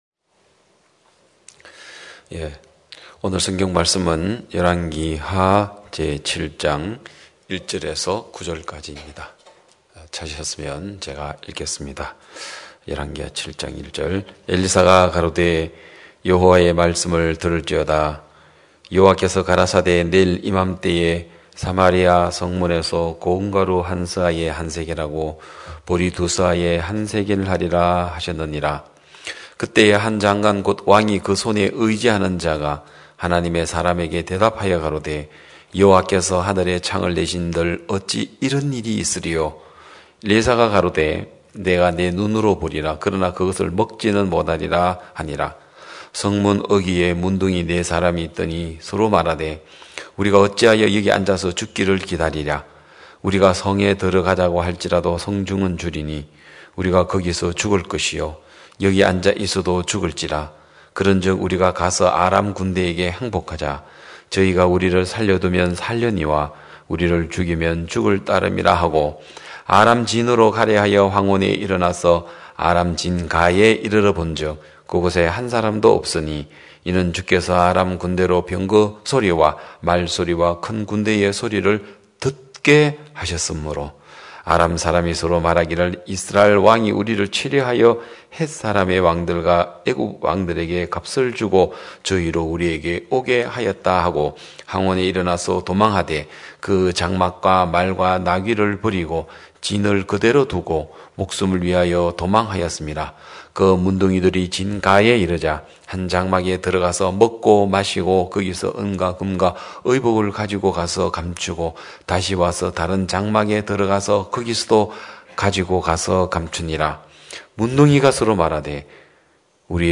2021년 11월 14일 기쁜소식양천교회 주일오전예배
성도들이 모두 교회에 모여 말씀을 듣는 주일 예배의 설교는, 한 주간 우리 마음을 채웠던 생각을 내려두고 하나님의 말씀으로 가득 채우는 시간입니다.